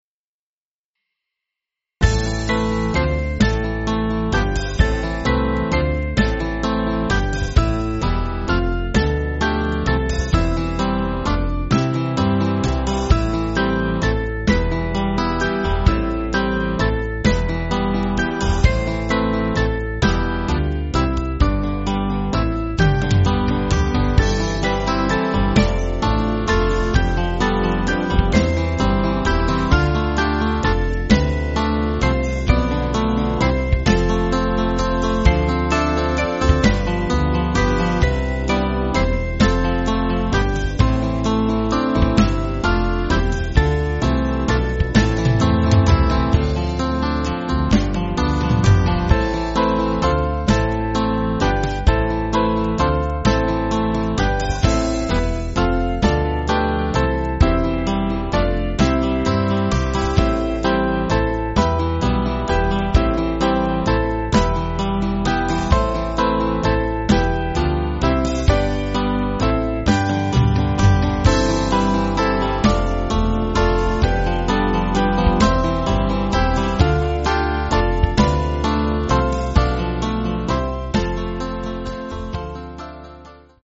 Small Band
(CM)   4/Am